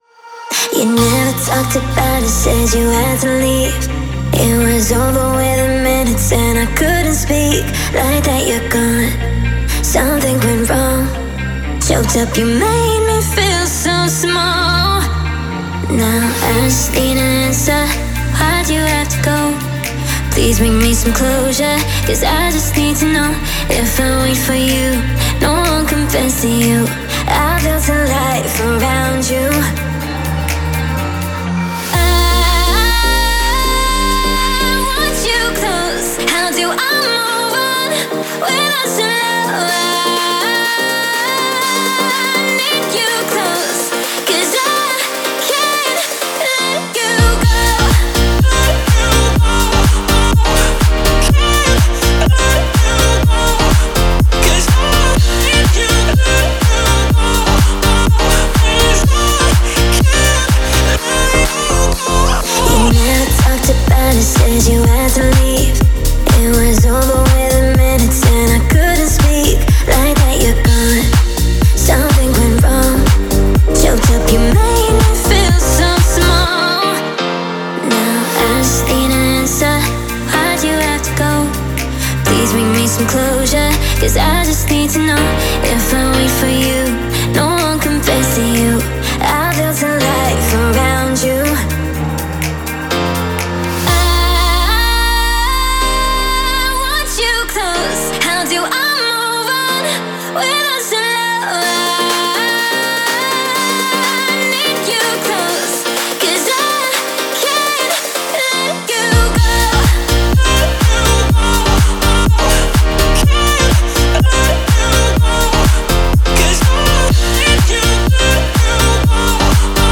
это яркий трек в жанре EDM, наполненный энергией и эмоциями.